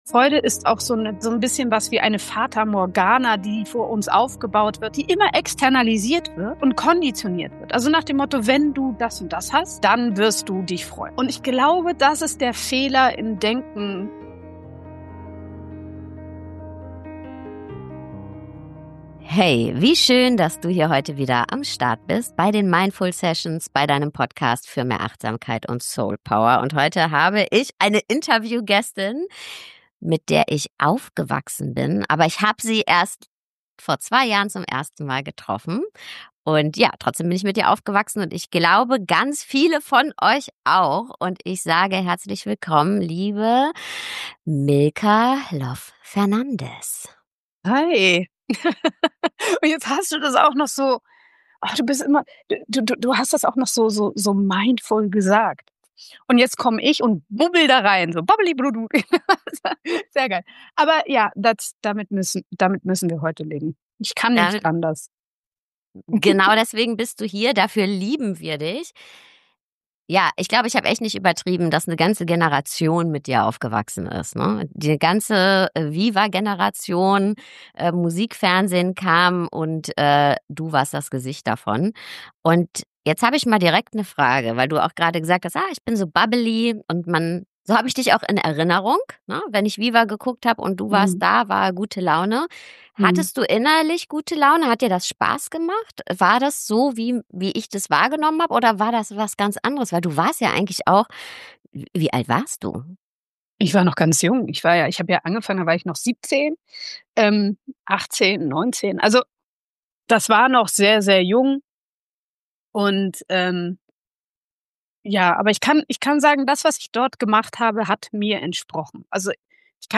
Wenn Freude schwer fällt - Interview mit Milka Loff Fernandes ~ The Mindful Sessions - Für mehr Achtsamkeit & Soulpower Podcast